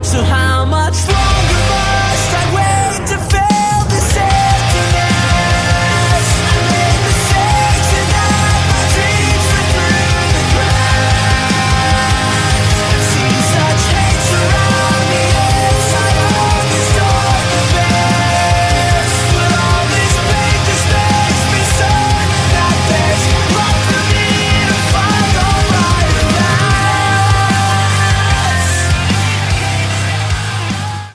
• Качество: 192, Stereo
Хороший пост-хардкор